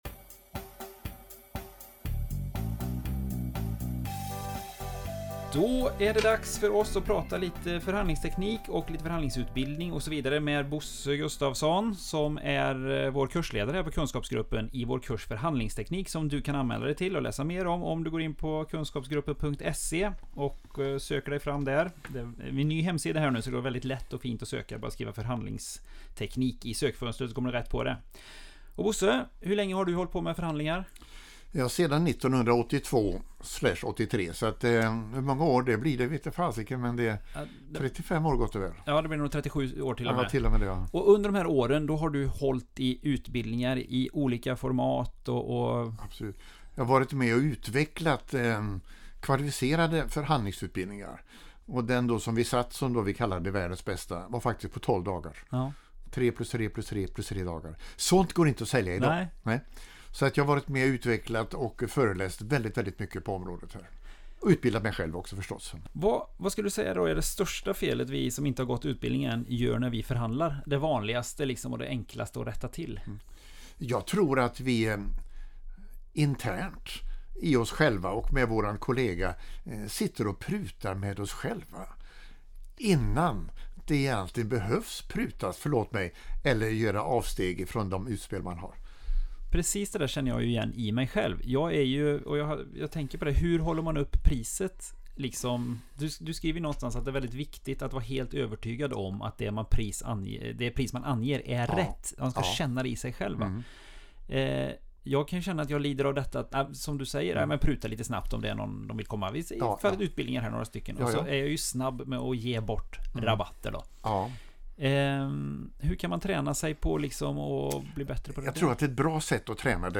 I den här intervjun ger kursledaren några tips.